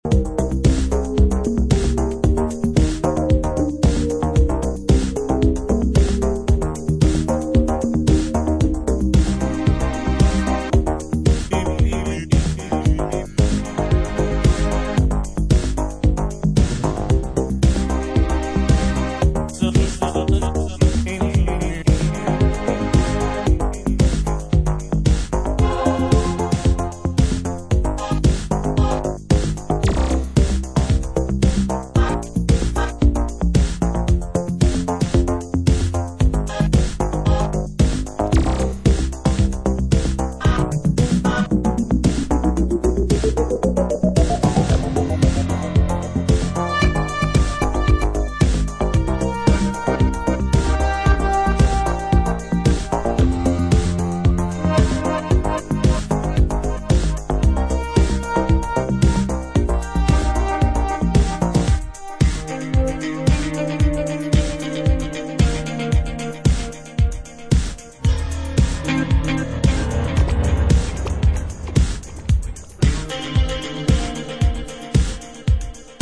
deep and melancholic remix